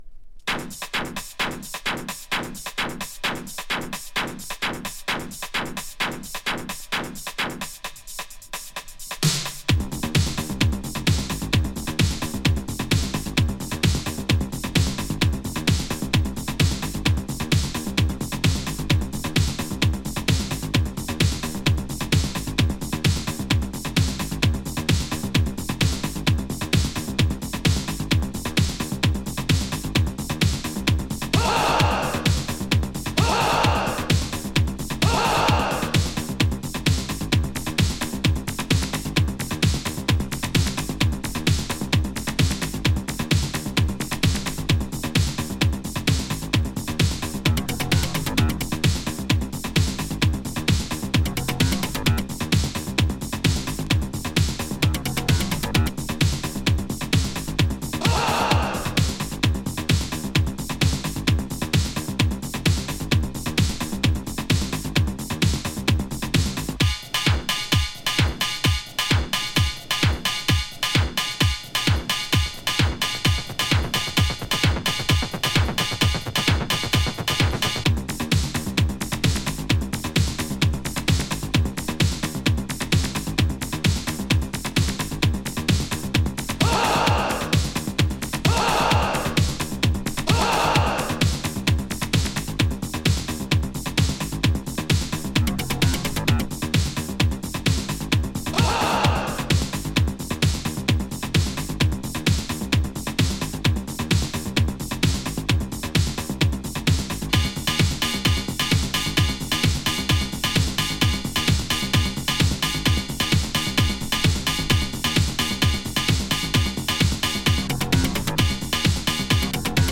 インダストリアル・ダンス！
テクノ〜ニューウェイヴをクロスオーヴァーするダークなインダストリアル～エレクトロ・ダンスミュージック！
【EBM】【NEW WAVE】